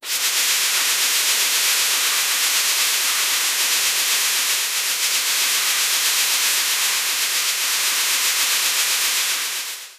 moisture-sound-effect-joqvqdnj.wav